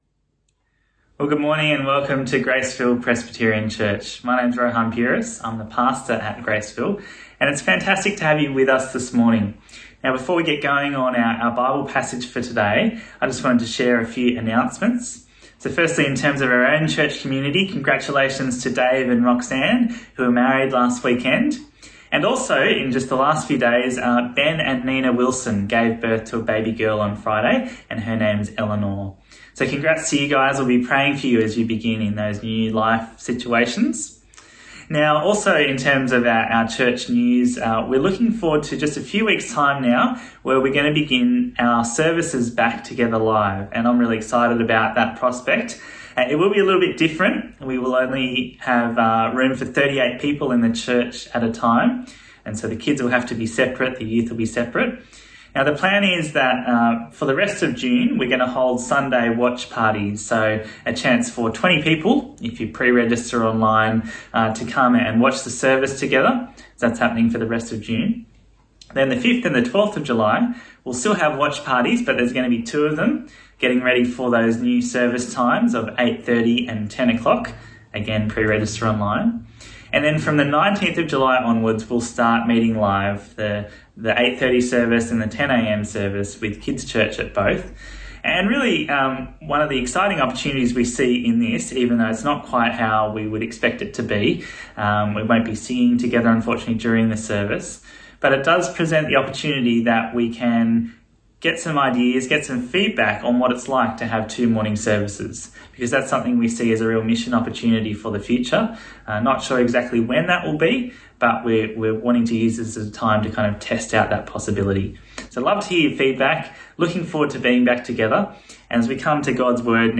Bible Reading: 1 Peter: 1-11 Speaker: